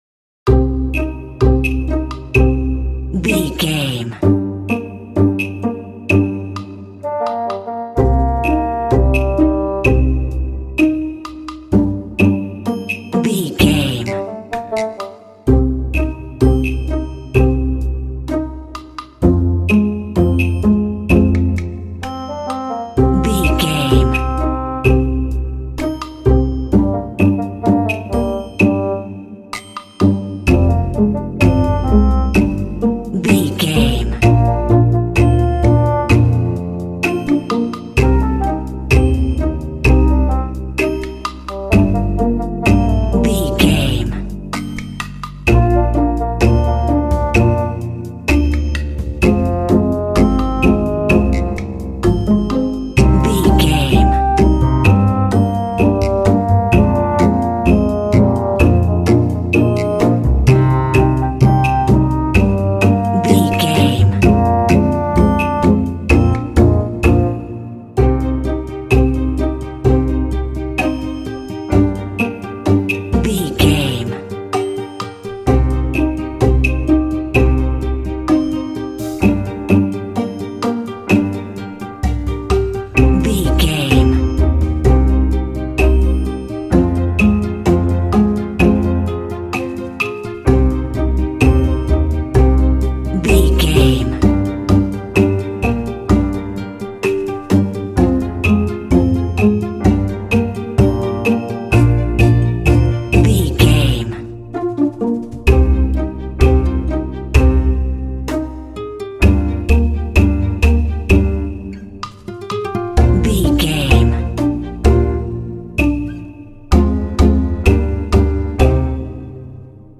This quirky track features a bassoon and plucked strings.
Aeolian/Minor
funny
playful
foreboding
suspense
bassoon
strings
percussion
piano
contemporary underscore